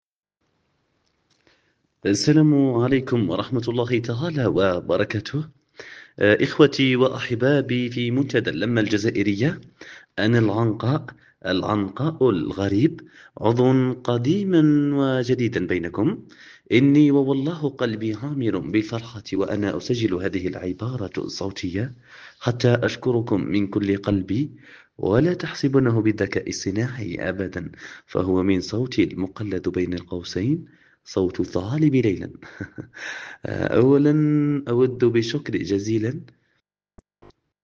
ليس من عادتي أغير صوتي إلا في الندوات الرسمية بالأنجليزية ..عندها نكهة 🤎